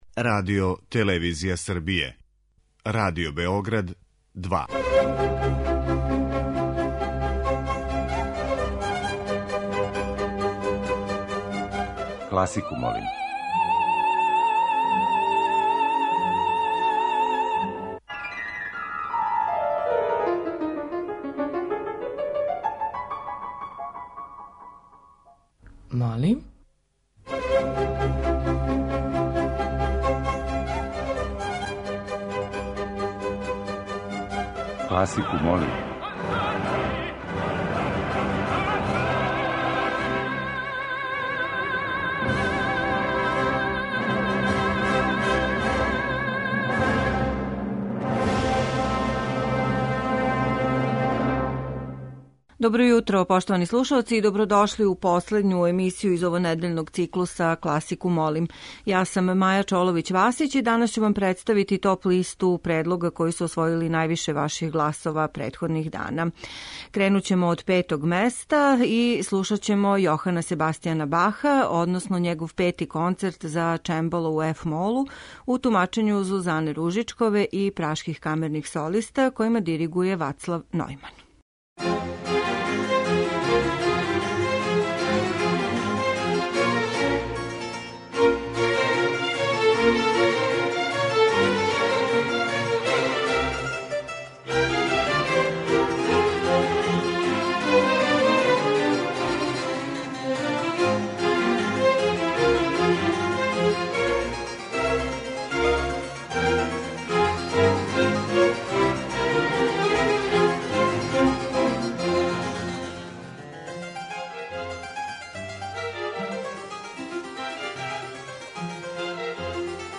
Средњевековне и ренесансне игре
Избор за недељну топ-листу класичне музике Радио Београда 2